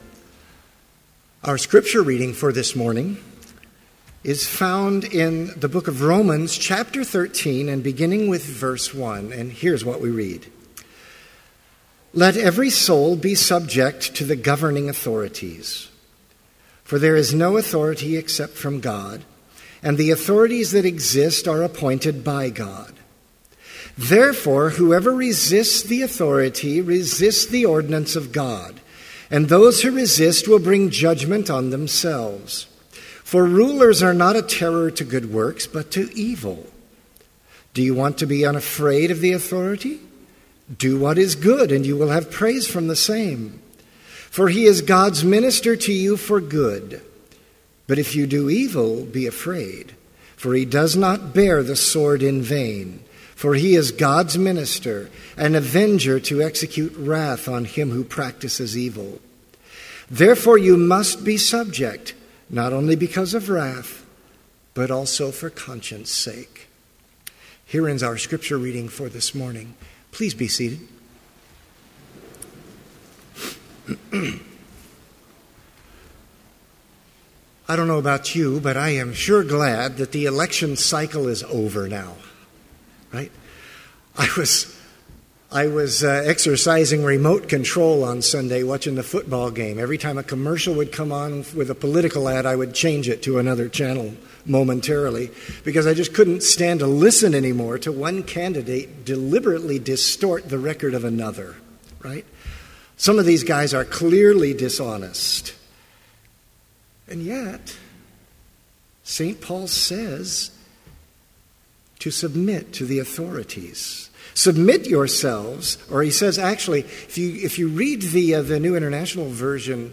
Complete Service
• Hymn 12, Come, Thou Almighty King
• Homily
This Chapel Service was held in Trinity Chapel at Bethany Lutheran College on Wednesday, November 5, 2014, at 10 a.m. Page and hymn numbers are from the Evangelical Lutheran Hymnary.